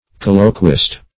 \Col"lo*quist\